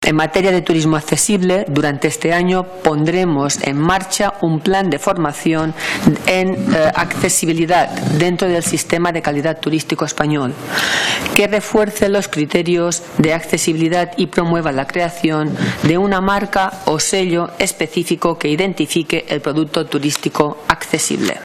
Así lo anunció la secretaria de Estado de Turismo, Isabel Oliver, el 29 de enero, al presentar en el Congreso la partida de su Departamento en los próximos Presupuestos.